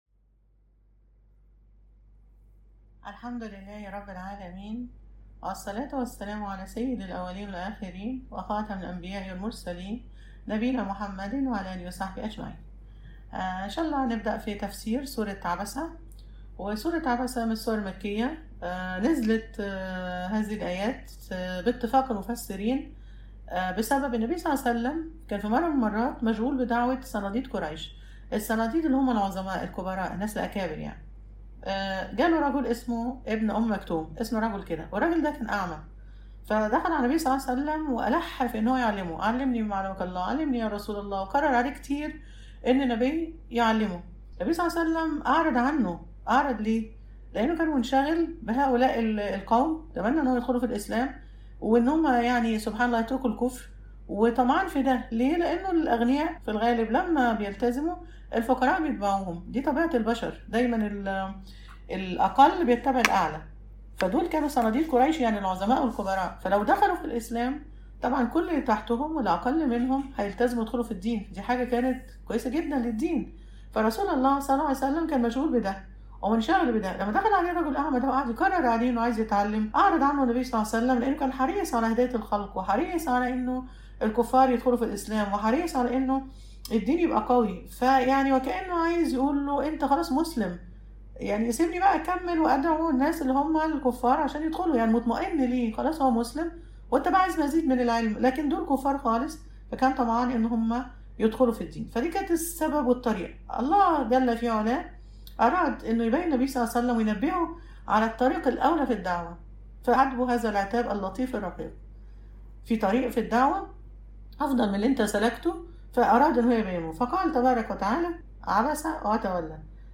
المحاضرة الرابعة_سورة عبس